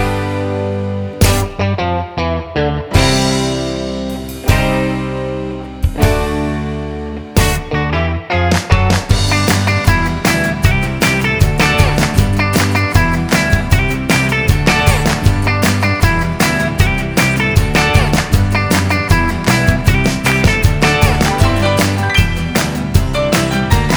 Two Semitones Down Pop (1980s) 2:30 Buy £1.50